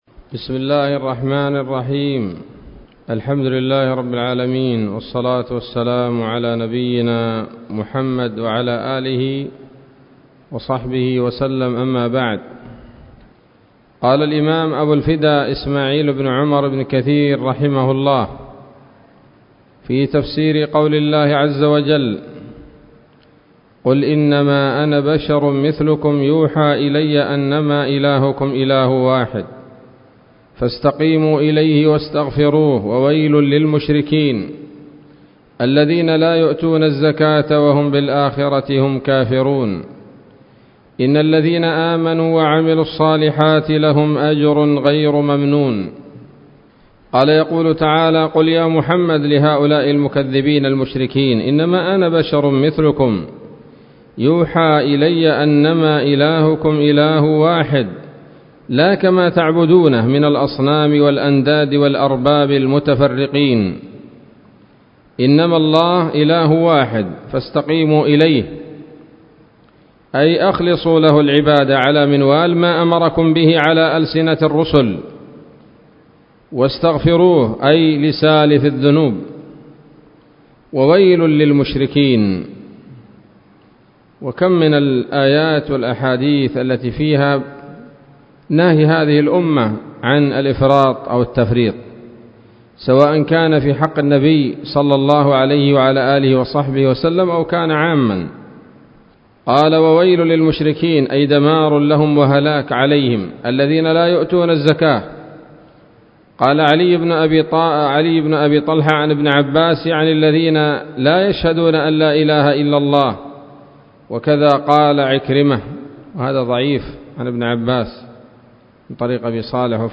الدرس الثاني من سورة فصلت من تفسير ابن كثير رحمه الله تعالى